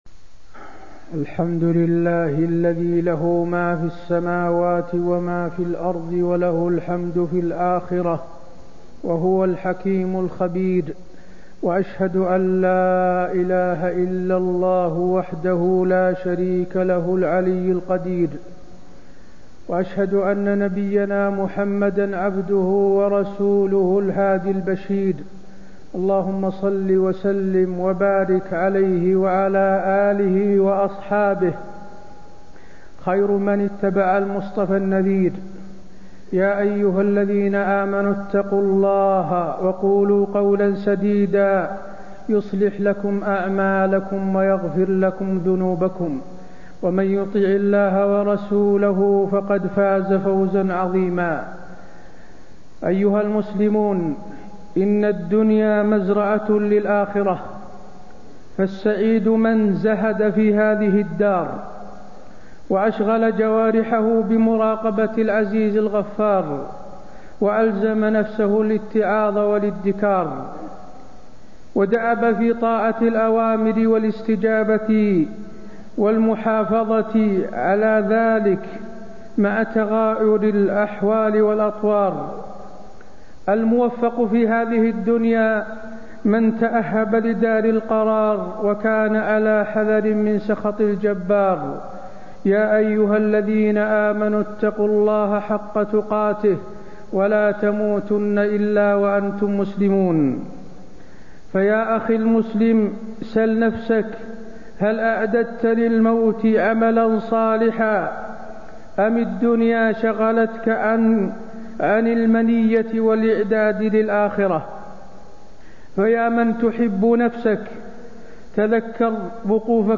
تاريخ النشر ٢ شعبان ١٤٣٣ هـ المكان: المسجد النبوي الشيخ: فضيلة الشيخ د. حسين بن عبدالعزيز آل الشيخ فضيلة الشيخ د. حسين بن عبدالعزيز آل الشيخ حقيقة الدنيا الفانية